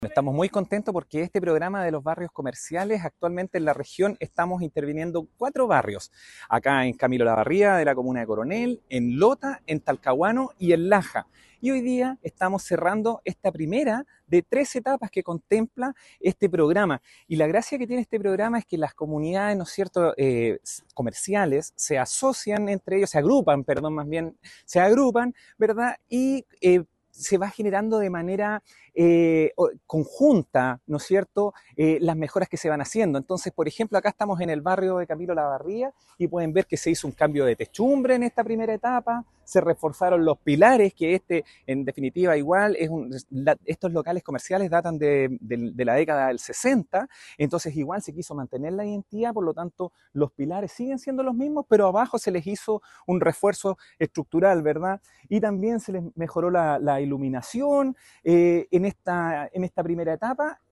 El Seremi de Economía, Fomento y Turismo, Christian Cifuentes Bastías, destacó la importancia de la asociatividad en este proceso, señalando que “las comunidades comerciales se agrupan y se van generando de manera conjunta las mejoras que se van haciendo”.